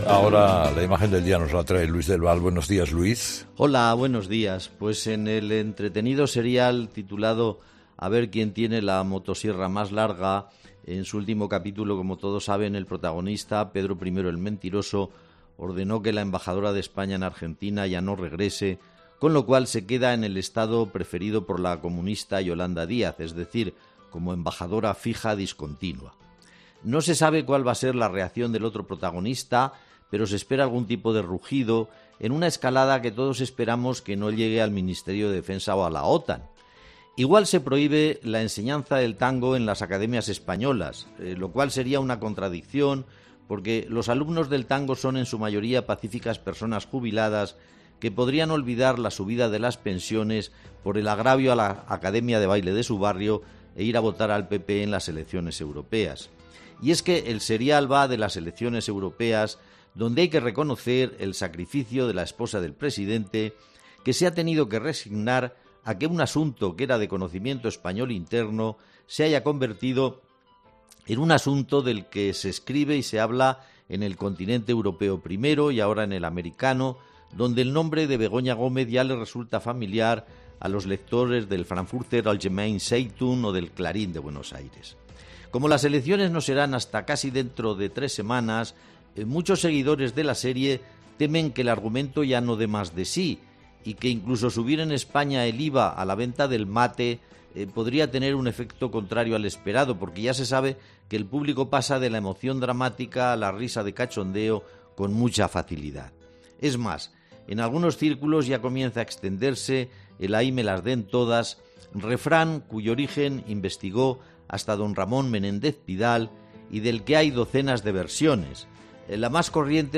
Habla el profesor de la decisión de España de retirar definitivamente a la embajadora en Argentina